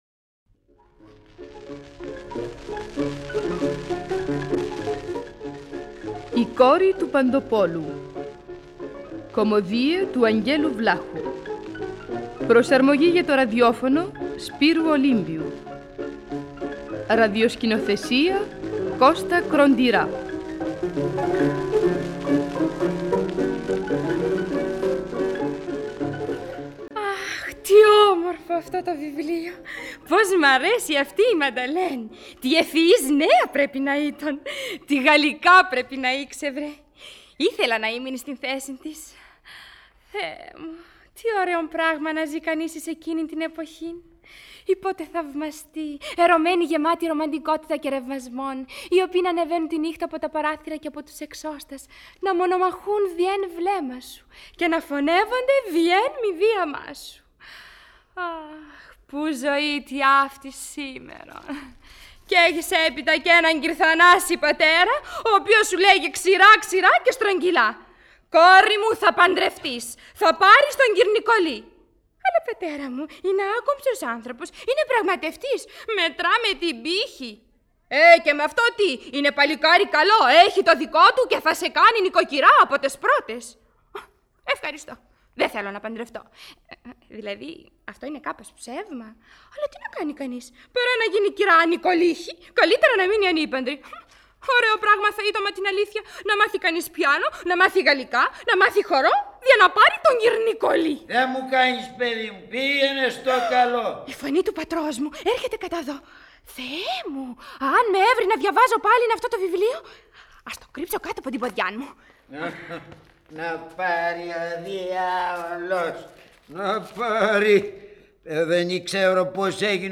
ραδιοφωνικά θεατρικά έργα